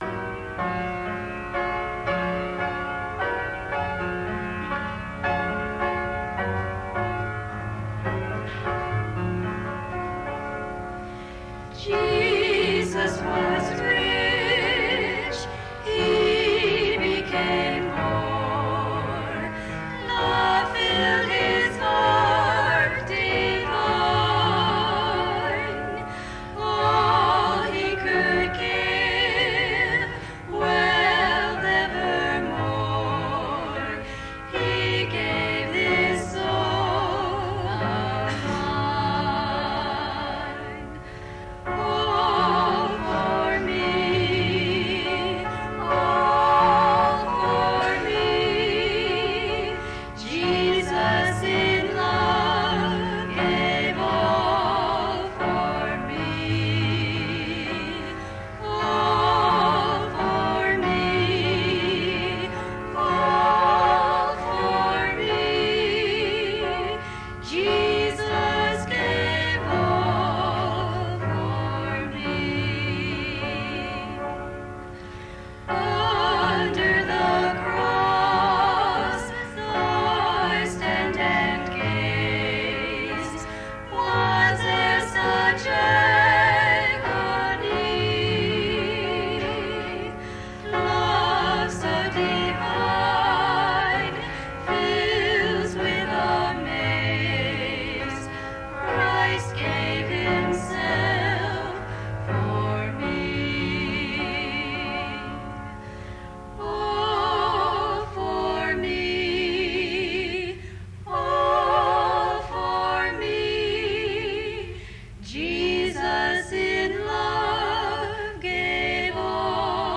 As with our quartets, trios have also had a part in proclaiming the wonders of our Lord God.
jcac-1985-Trio-All-For-Me.m4a